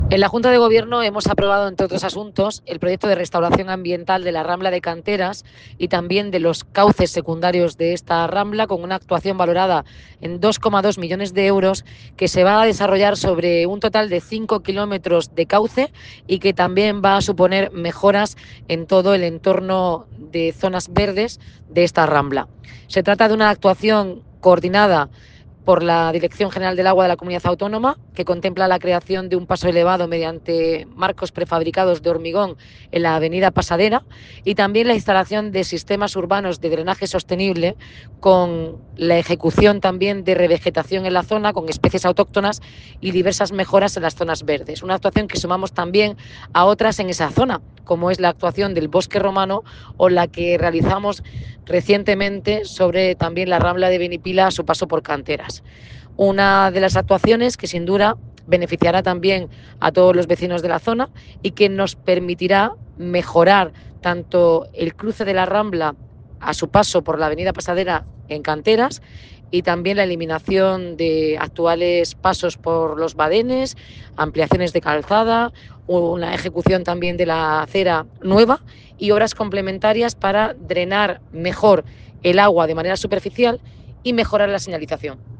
Enlace a Declaraciones de Noelia Arroyo sobre reforma integral de la Rambla de Canteras